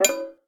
06531 percussive blocked ding
application blocked cancel click drum interface percussive sfx sound effect free sound royalty free Sound Effects